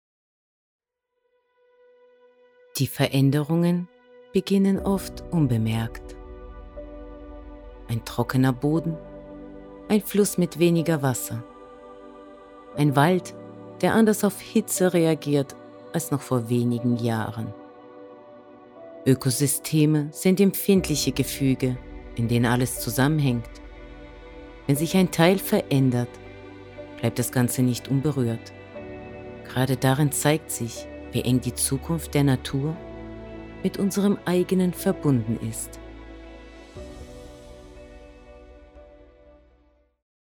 Deutsche Voice-over-Sprecherin – warm & klar.
Studioqualität.
Sprechprobe: Industrie (Muttersprache):
German voice-over artist (warm, clear female voice) for ads, explainer videos and narration.
Demo Doku Ökosystem mp3.mp3